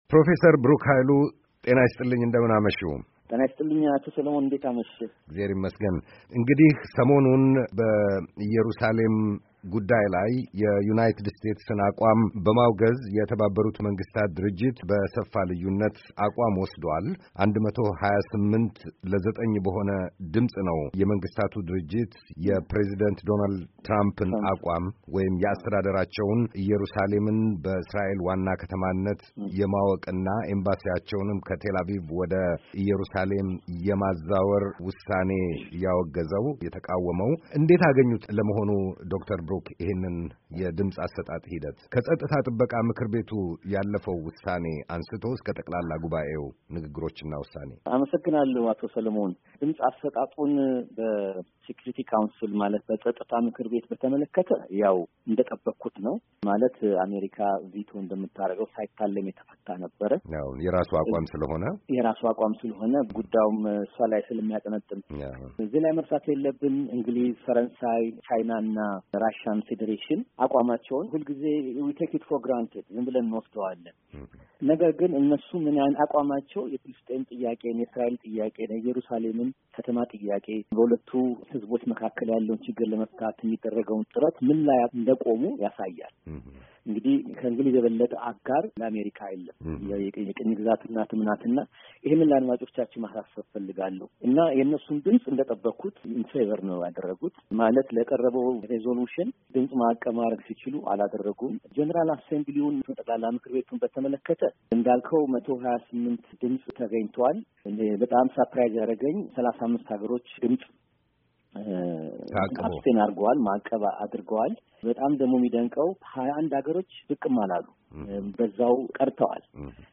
Jerusalem, US, UN, Ethiopia: Interview